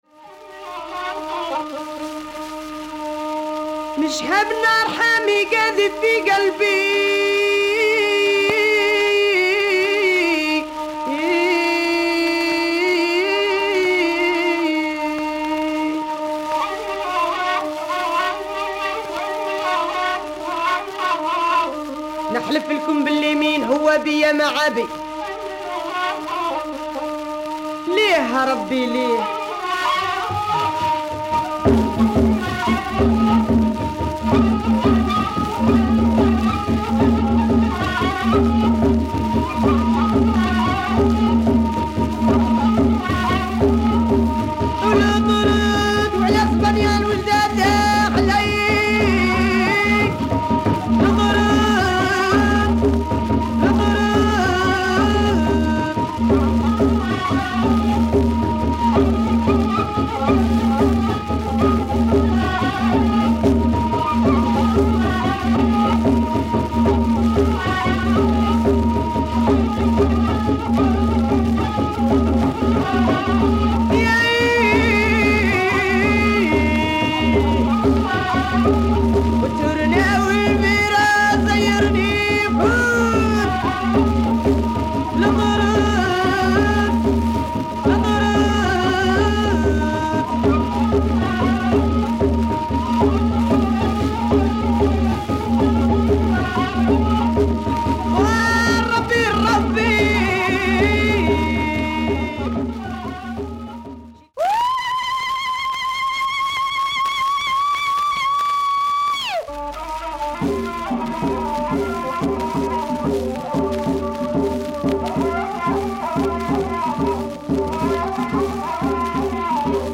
Very rare female proto rai from Algeria.